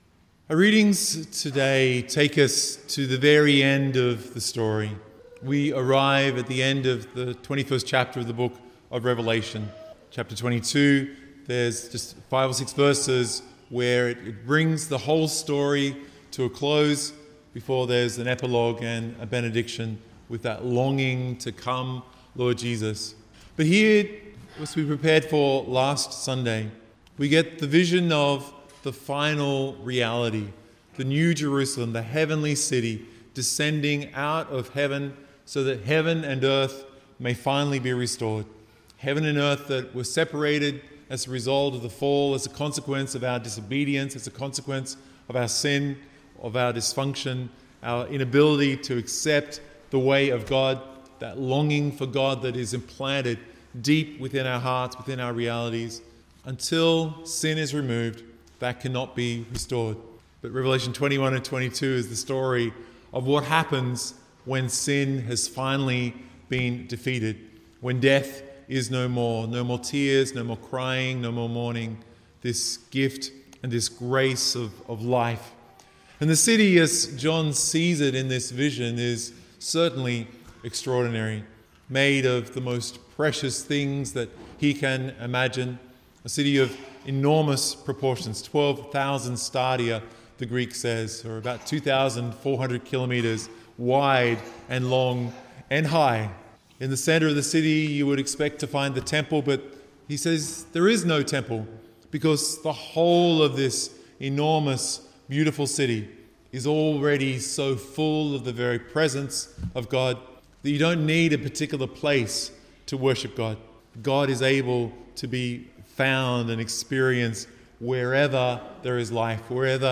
Download or Play MP3 MP3 media (Vigil)